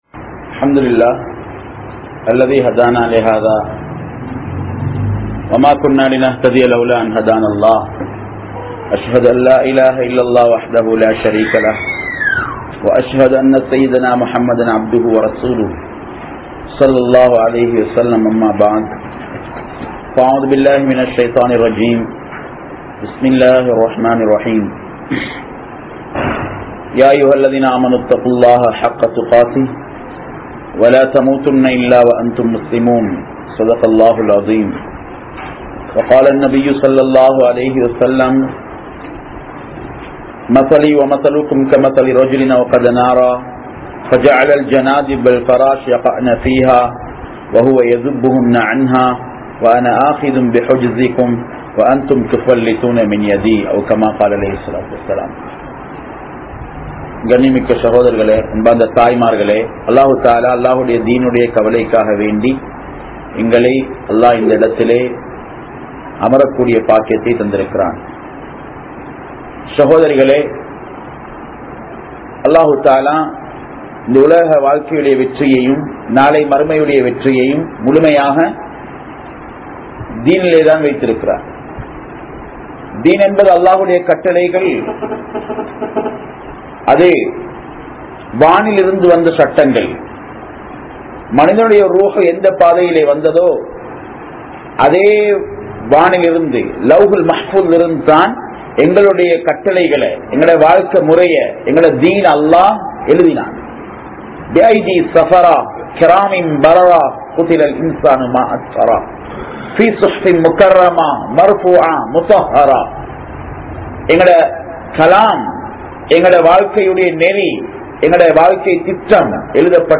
Veettil Irunthuthaan Dheen Valarum (வீட்டில் இருந்துதான் இஸ்லாம் வளரும்) | Audio Bayans | All Ceylon Muslim Youth Community | Addalaichenai